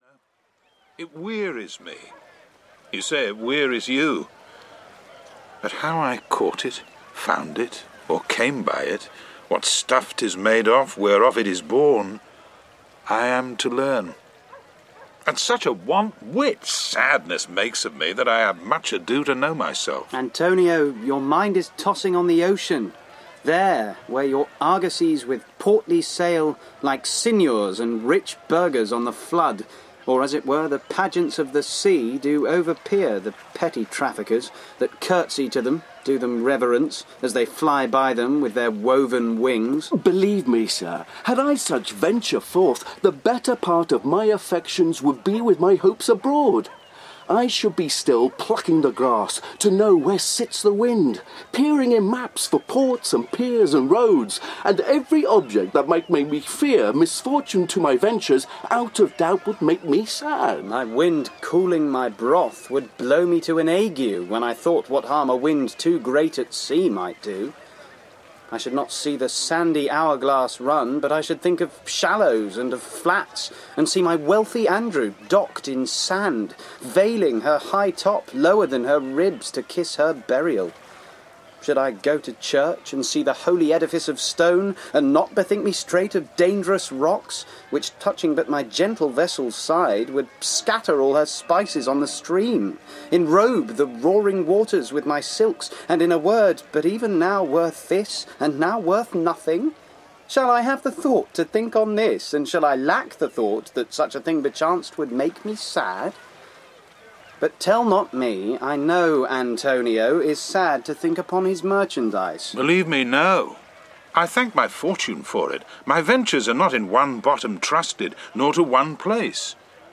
The Merchant of Venice (EN) audiokniha
Ukázka z knihy
• InterpretAntony Sher, Roger Allam, Emma Fielding